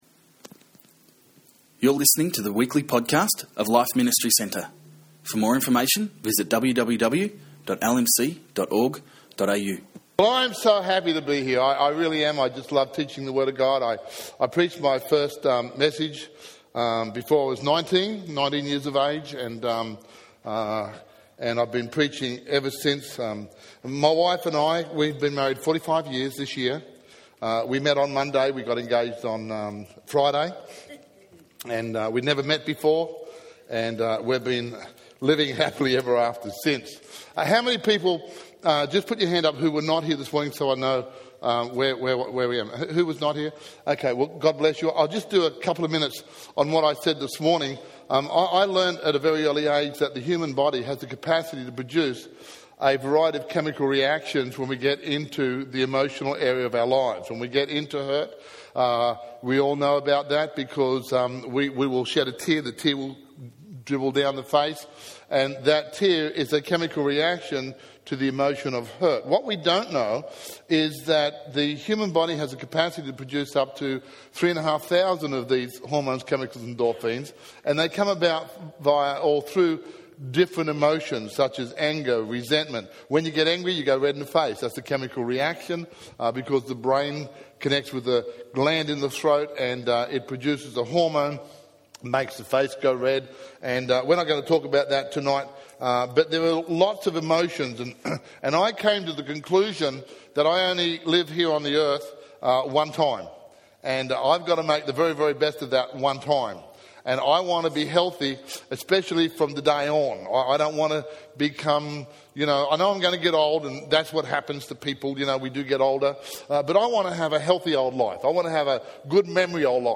Continued from AM Service. People are spending big dollars on vitamins and medications to correct chemical imbalances in their bodies.